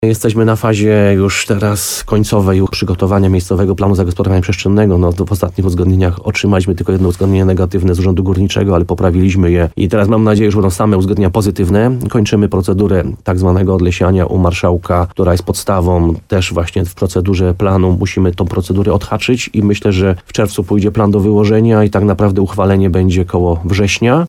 Jak mówi burmistrz Krynicy-Zdroju Piotr Ryba, jest plan, aby jeszcze jesienią tego roku wydać decyzję o powstaniu 100 miejsc parkingowych na ul. Świdzińskiego oraz przy starym osadniku borowinowym.